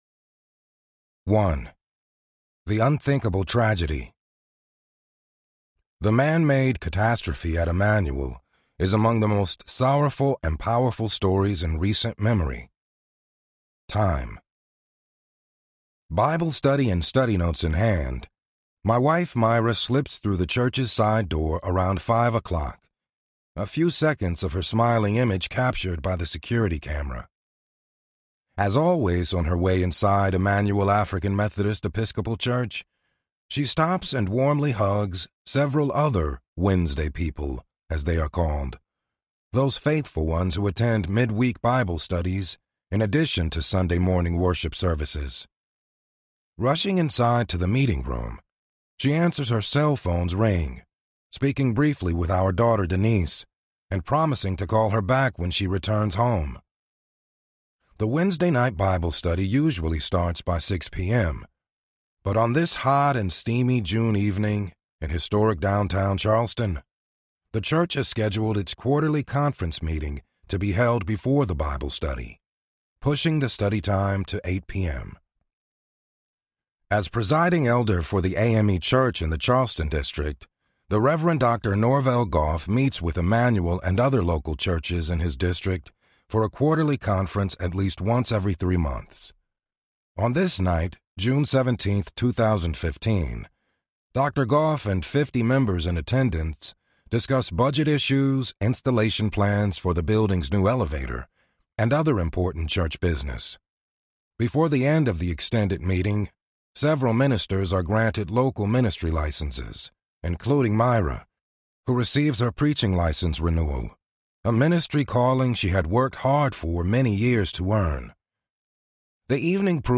Called to Forgive Audiobook
Narrator
6.0 Hrs. – Unabridged